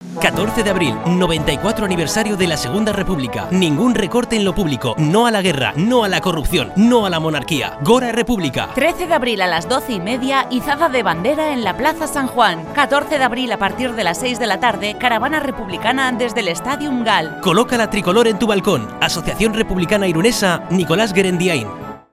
Cuñas en "Radio Irun" del 9 al 14 de abril